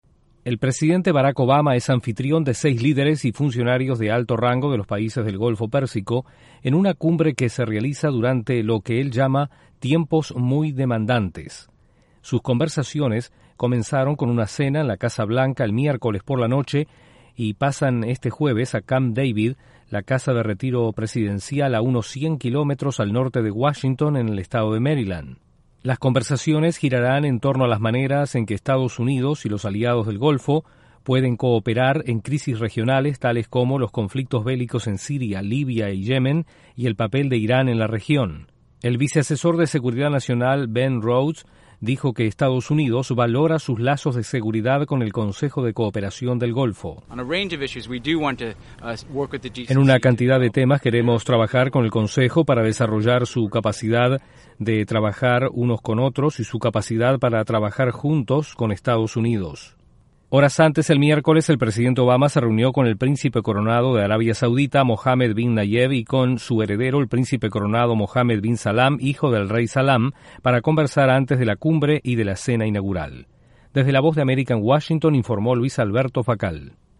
El presidente de EE.UU. Barack Obama es anfitrión de una cumbre de líderes del Golfo Pérsico. Desde la Voz de América en Washington informa